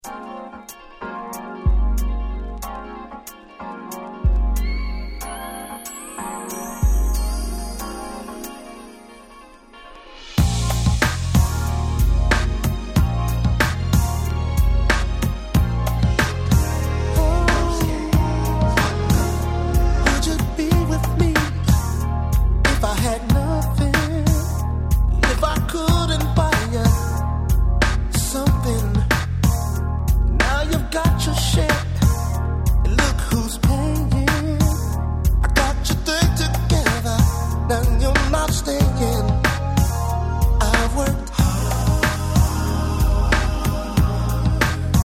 まさにNice 90's R&B !!